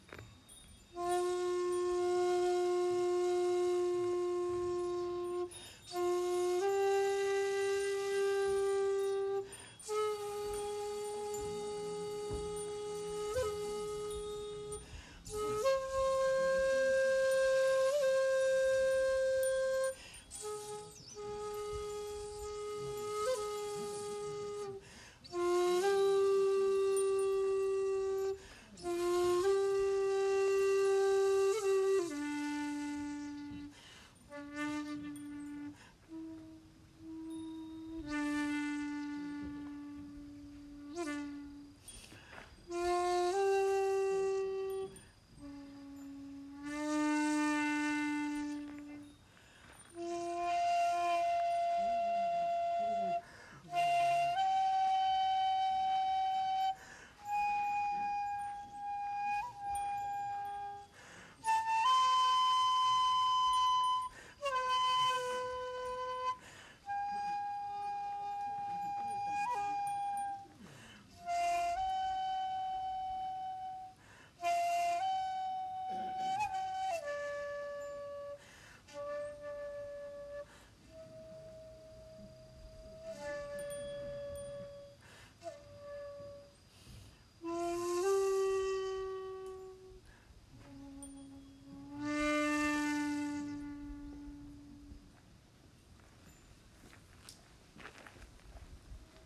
第八十八番大窪寺（医王山　遍昭光院　本尊：薬師如来）に到着(13:20)。
そしてもちろん尺八を吹奏しました。
（音源：本堂前で尺八吹奏「水鏡」）